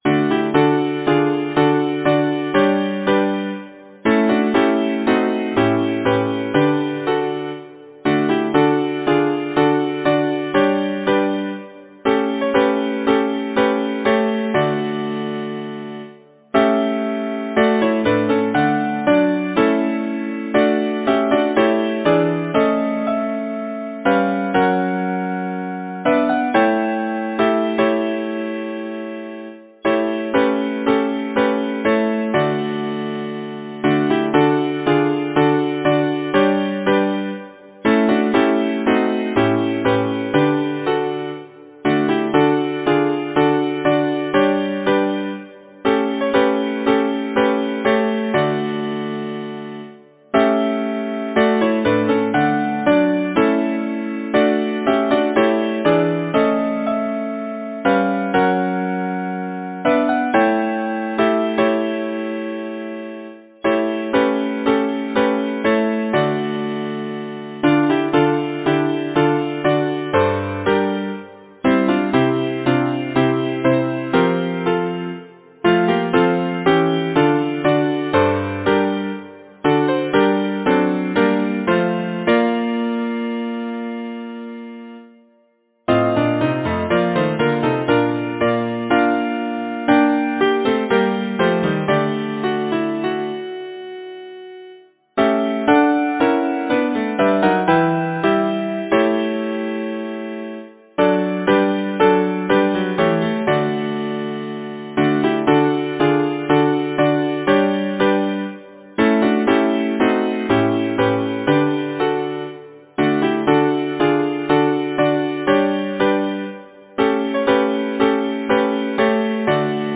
Title: The summer days are over Composer: Simeon P. Cheney Lyricist: Number of voices: 4vv Voicing: SATB Genre: Secular, Partsong
Language: English Instruments: A cappella